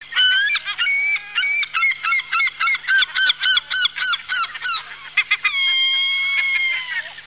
Goéland leucophée
Larus michahellis
goeland.mp3